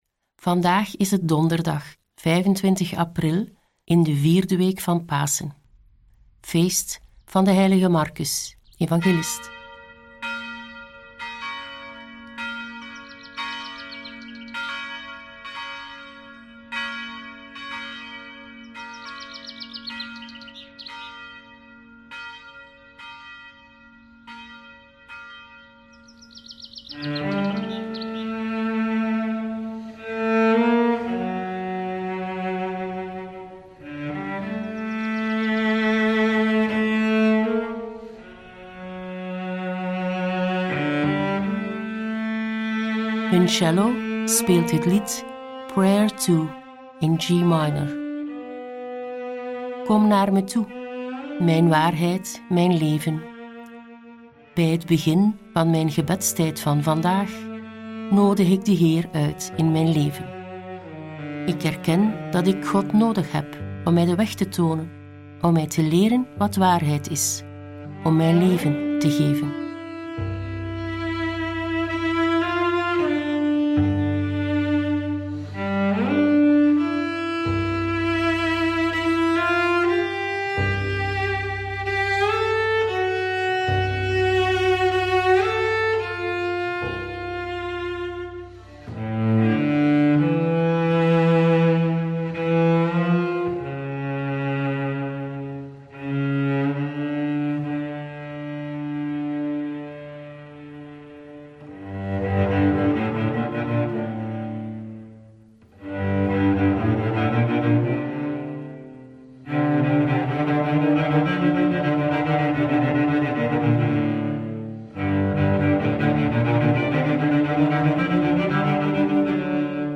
De muzikale omlijsting, overwegingen y begeleidende vragen helpen je om tot gebed te komen.